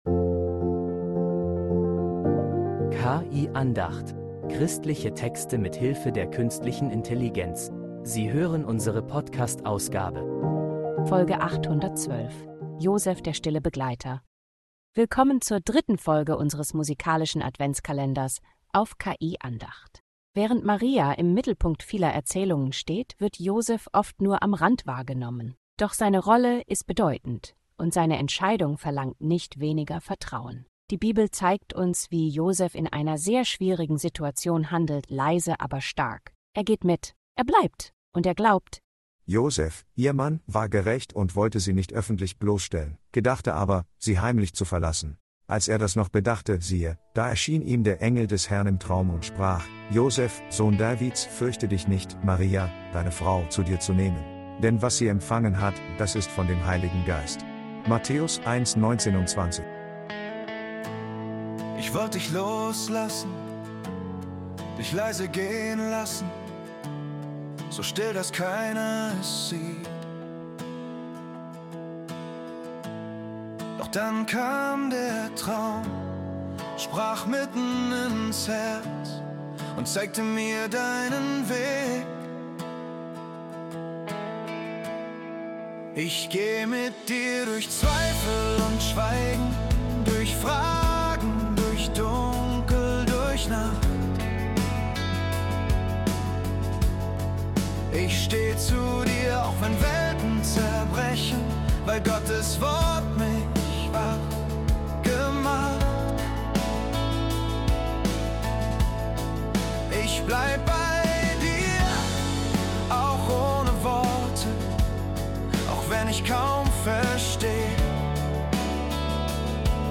musikalisch erzählt in einem sanften Soft-Rock-Stück.